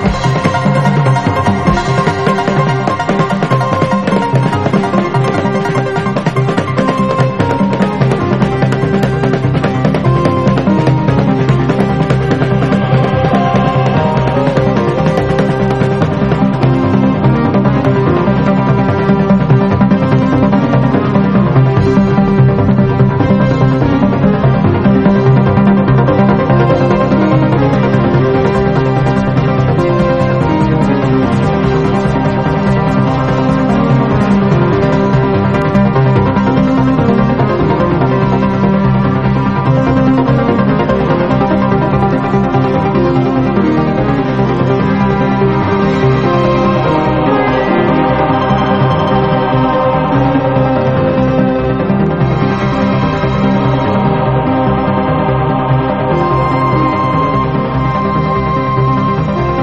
ニュー・エイジ～オーガニックなシンセ・アンビエント作品！
ポコポコと泡沫のように現れては消えるシンセ・サウンドが身体に心地よく響く
ミニマルなアンビエント調の
アブストラクトなビートが効いた